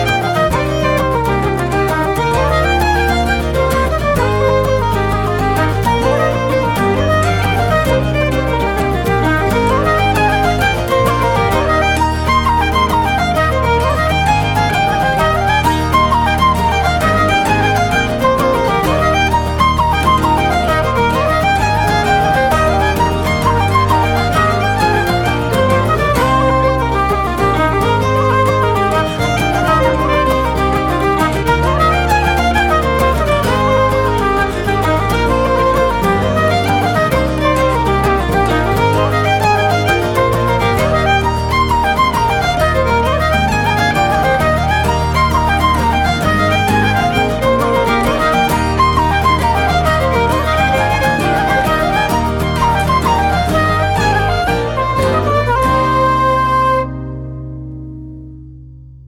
Fiddle
Flute
Guitars